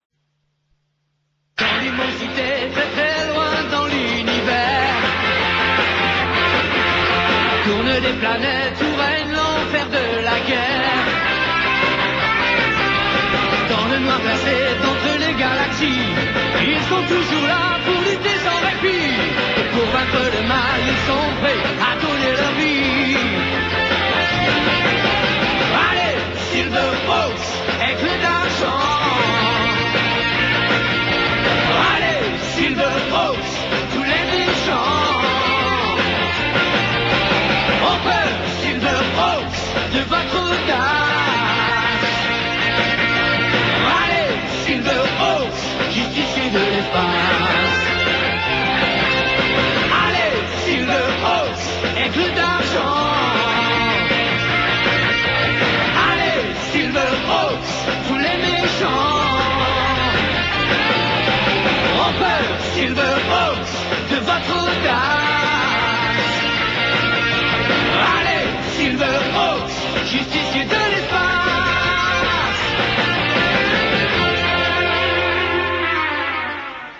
l’audio du générique
un générique très dynamique